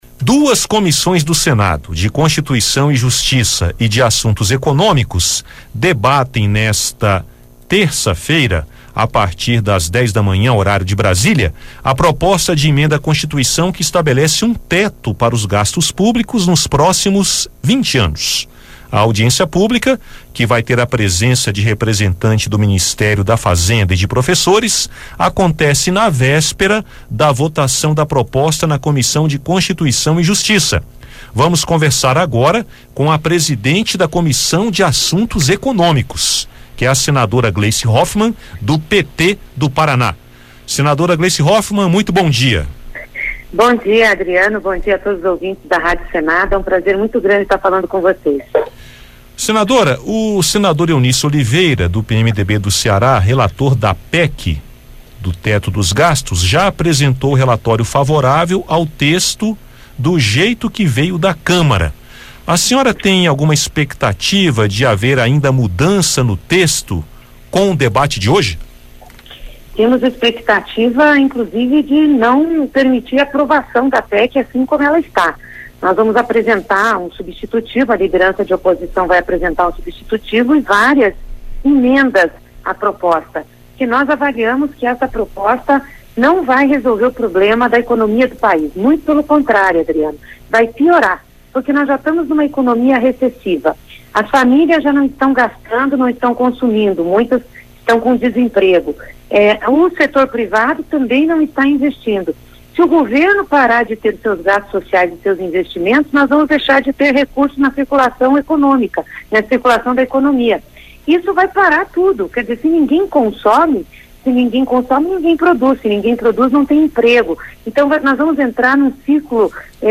A senadora conversou sobre os efeitos da PEC e sua tramitação com o jornalista